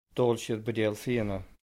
Intonational differences between Irish dialects
Irish intonation
An Cheathrú Rua, Co. Galway